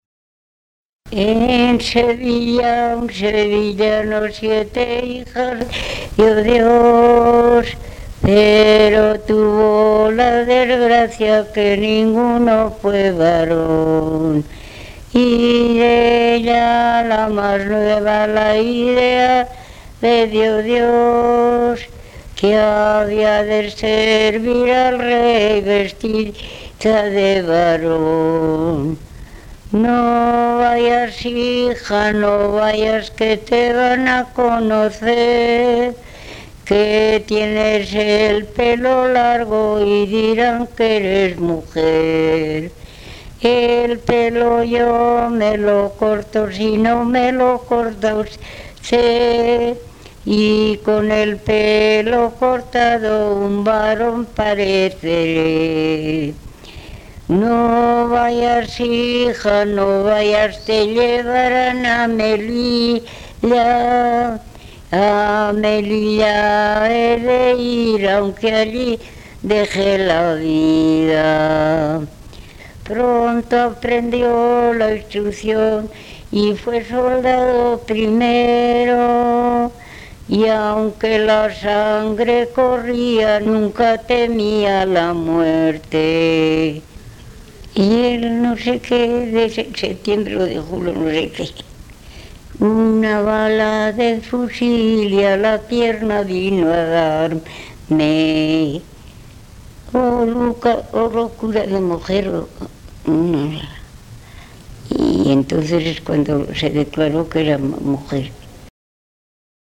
Clasificación: Romancero
Lugar y fecha de grabación: Villavelayo, 11 de agosto de 1995